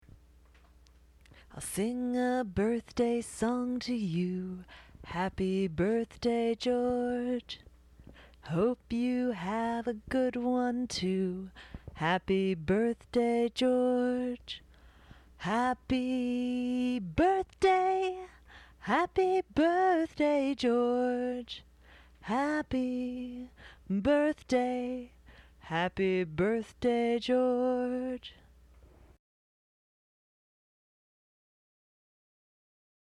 As you can tell from listening to them, I am not a professional singer. But what good is a Happy Birthday replacement if anyone can't just bust it out, with no accompaniment?